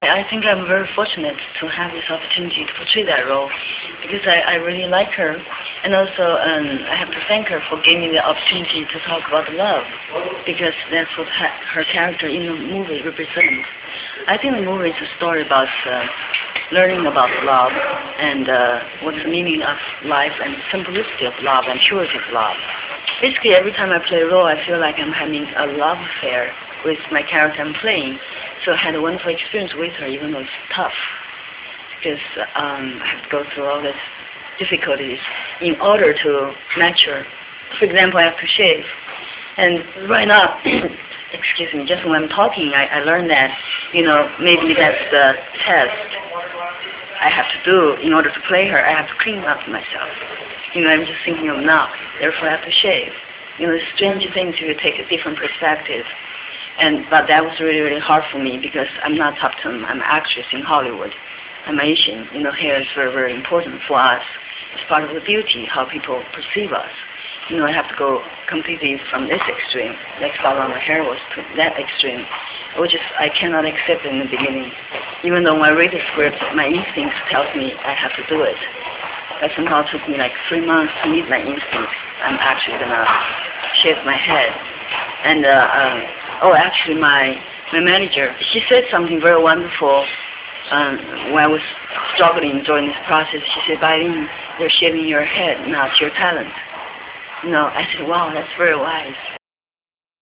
AsianConnections Interviews Bai Ling
Bai_Ling_Interview1.rm